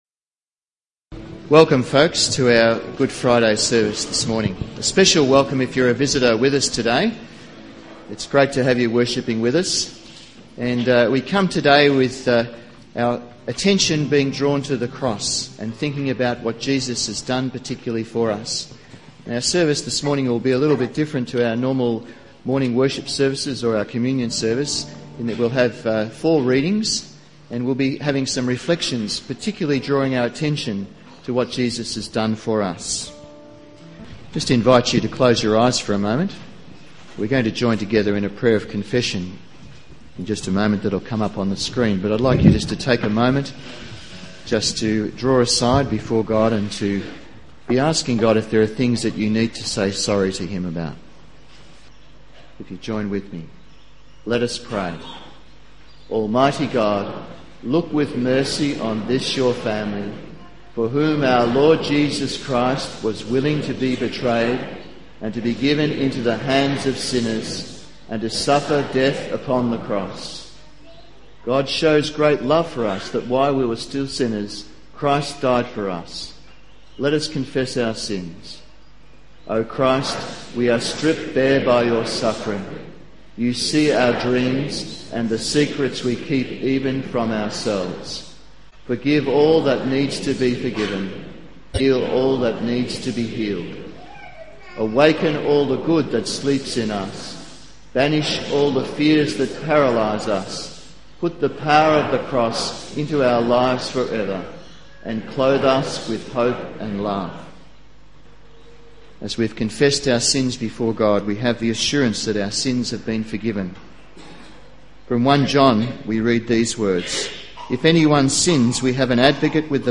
Download Download Bible Passage John 18-19 In this sermon